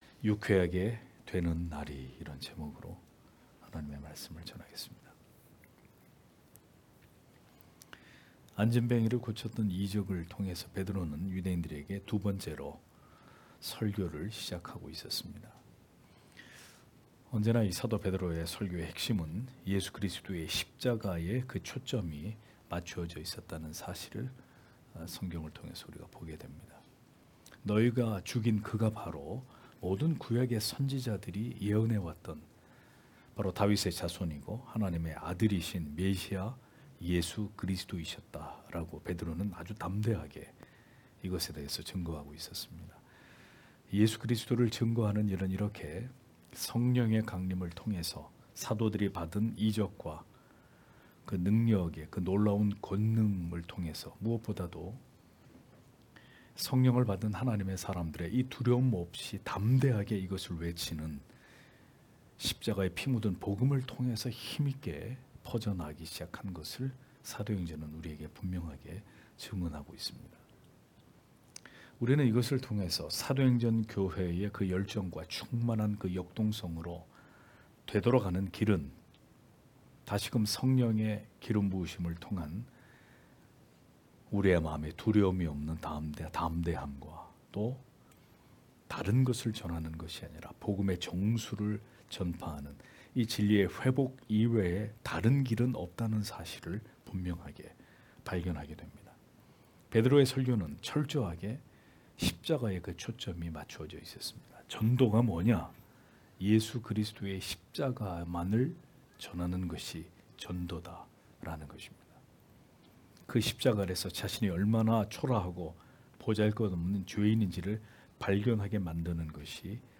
금요기도회 - [사도행전 강해 22] 유쾌하게 되는 날이 (행 3장 16-21절)